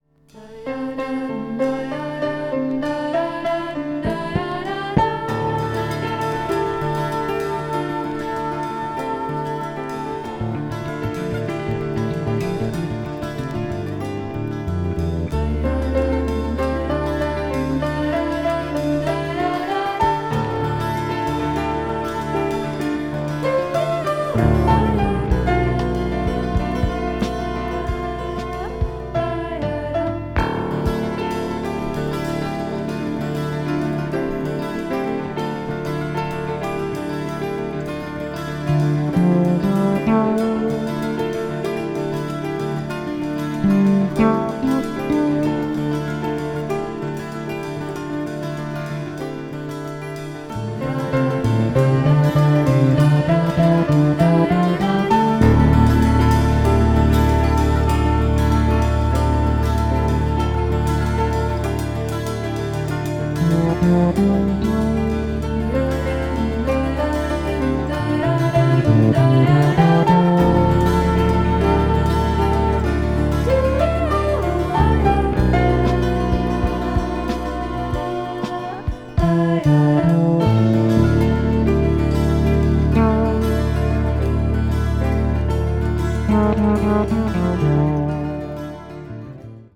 crossover   ethnic jazz   fusion   world music